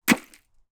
🌾 FARMING (8/8) - 100% REALISTIC:
- dig_REAL.wav (real digging)